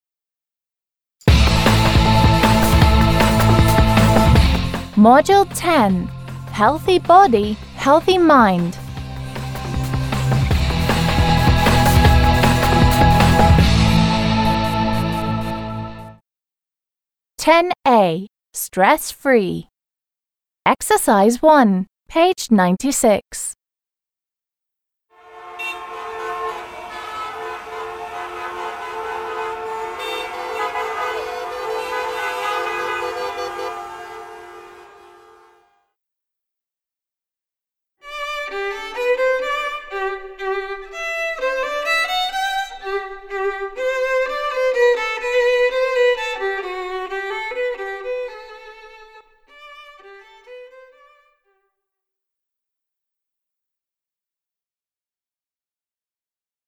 I can imagine busy streets with a lot of cars.
I hear a lot of noise.
I can imagine a comfortable arm-chair, quiet and pleasant melody.